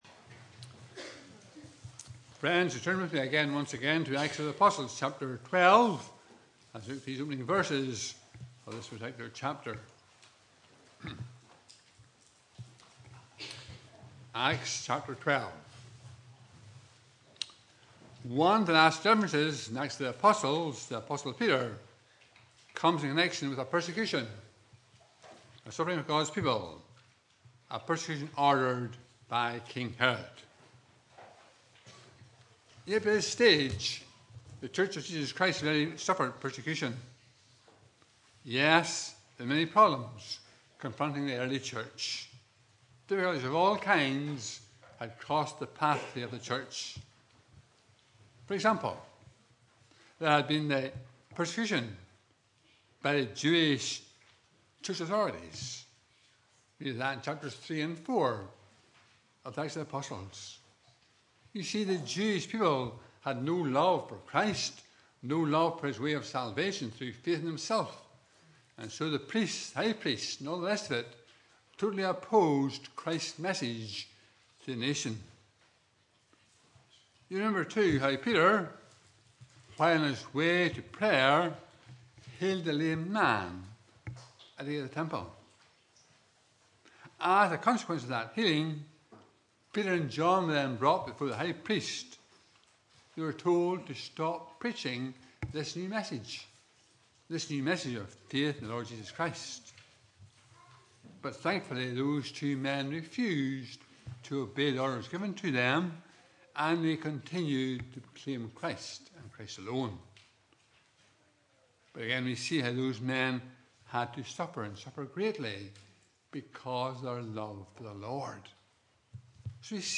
Passage: Acts 12:1-25 Service Type: Morning Service